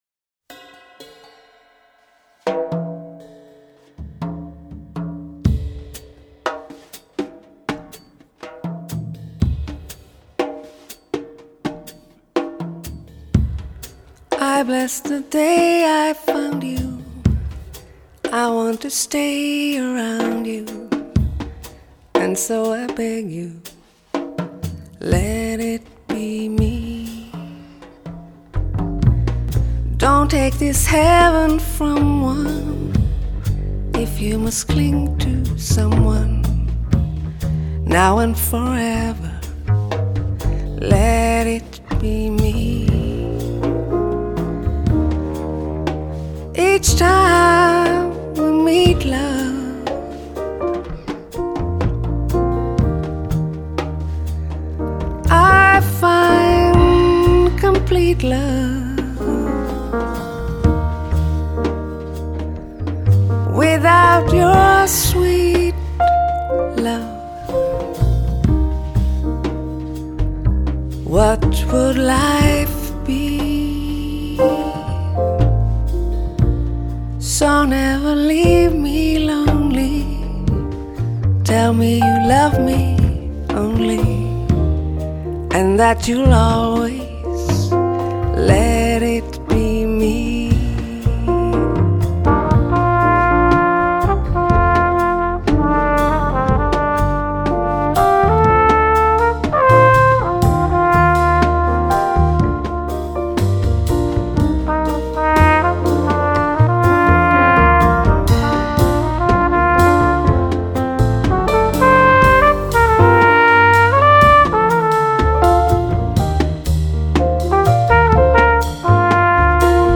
爵士及藍調 (499)
★ 來自北國挪威的清澈天籟，恬靜愜意又不失動人情感的癒療系爵士／流行天后！
★ 充滿通透感、清晰而明亮又不失夢幻感的圓潤嗓音，帶您體驗人聲最美的境地！